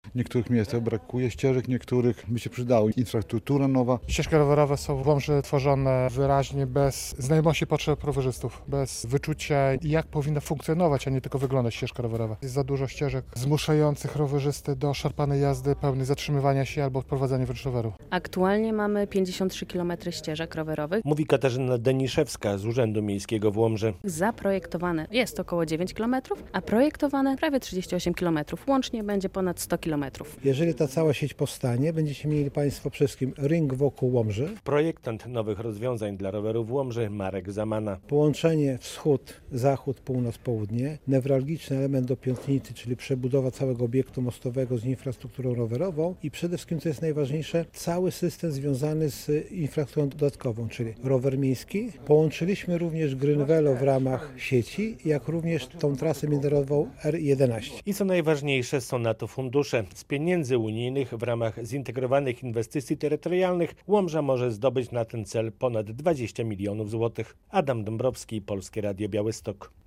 Konsultacje na temat ścieżek rowerowych w Łomży - relacja